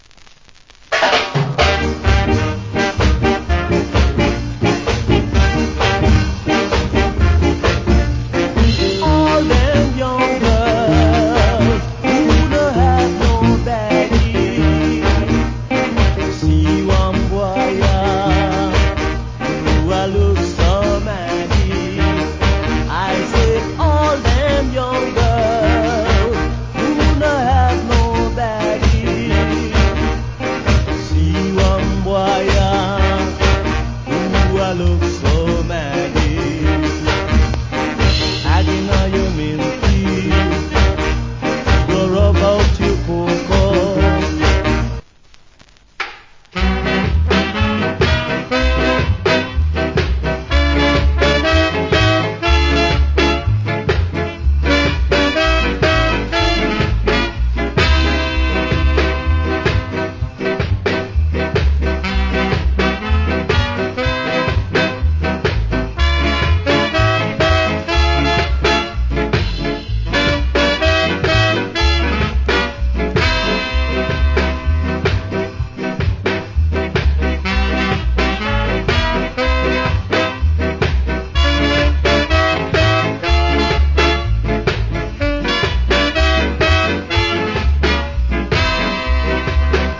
Nice Uptemp Ska Vocal.